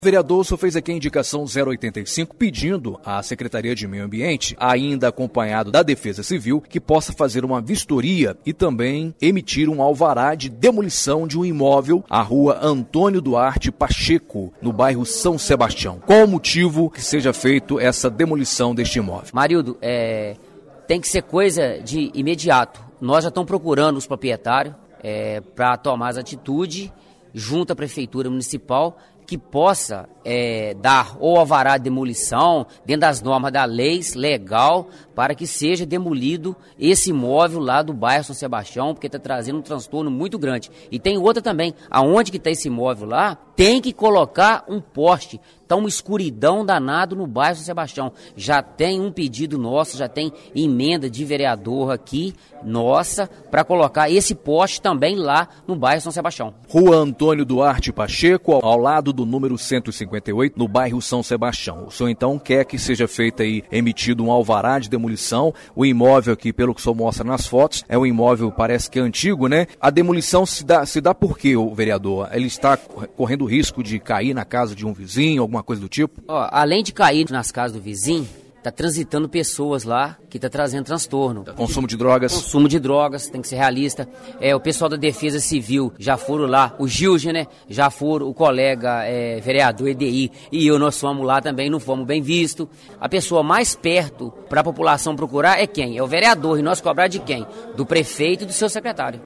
Em entrevista ao Informativo Câmara exibido na Rádio Educadora AM/FM – o vereador explicou os motivos.